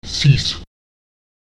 Lautsprecher sis [sis] neu